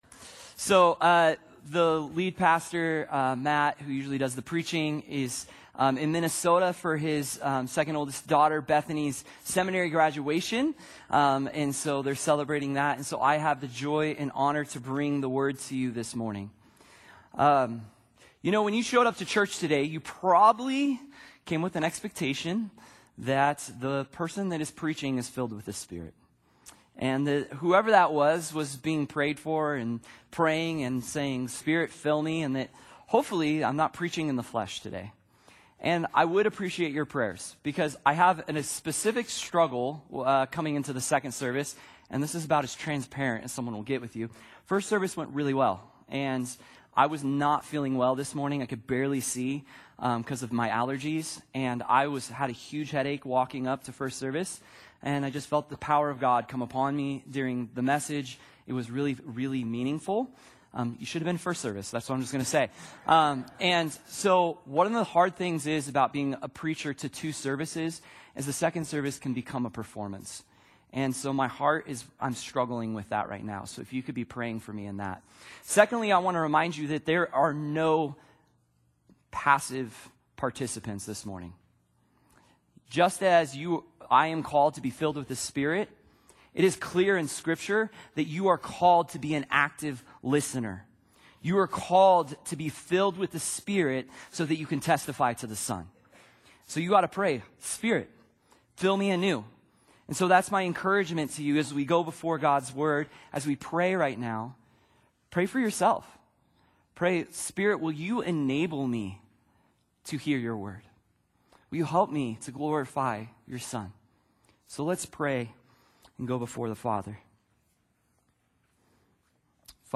Sermons - Solid Rock Christian Fellowship